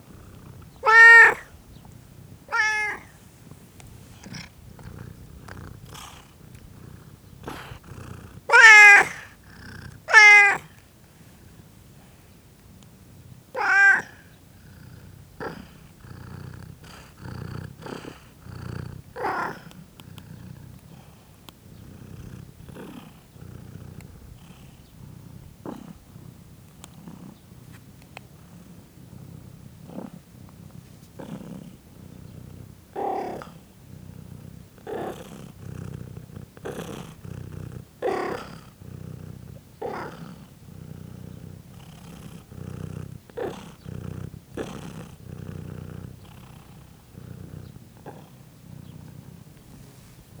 Directory Listing of //allathangok/parasztudvar/macska/
nyavogszuszogdorombol_gorestanya00.50.wav